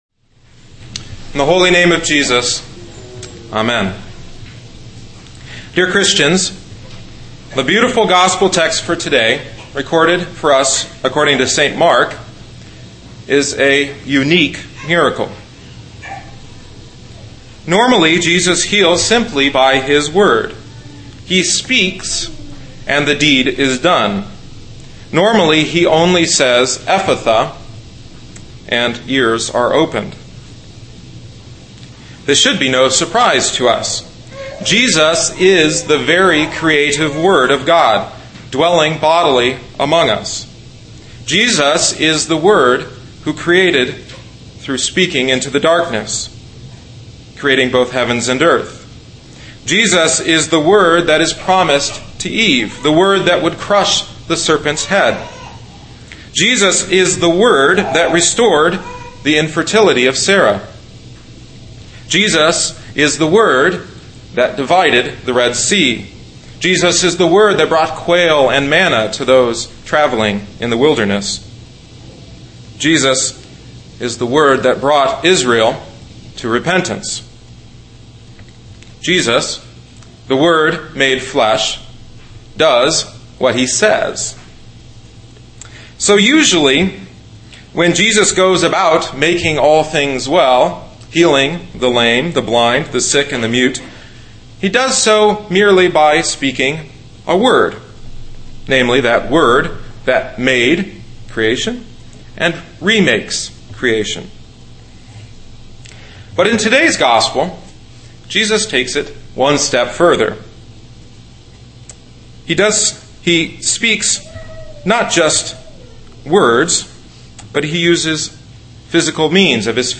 August 2010 Twelfth Sunday after Trinity Mark 7:31-37